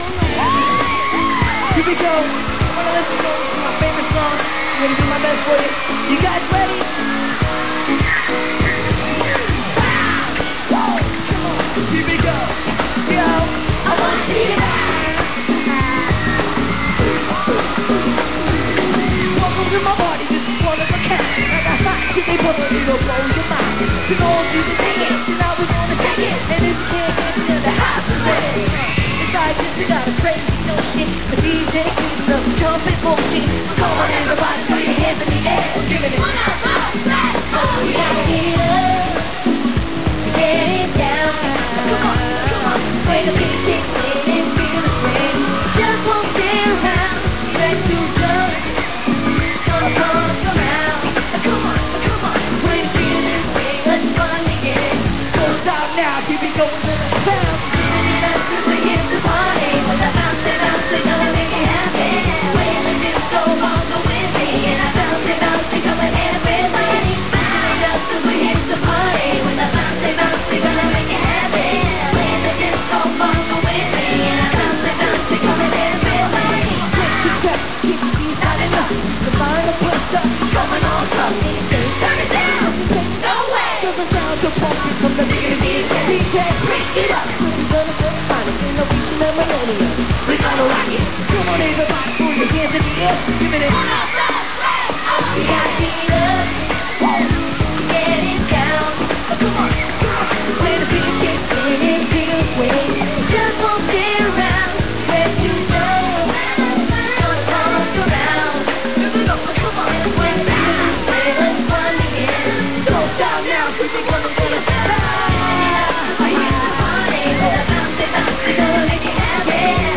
Sounds Recorded From TV Shows